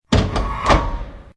CHQ_FACT_switch_popup.ogg